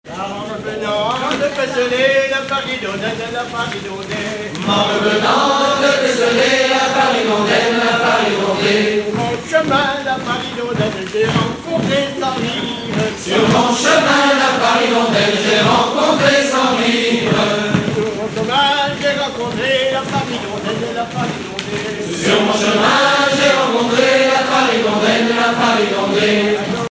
danse : passepied
Genre laisse
fêtes du chant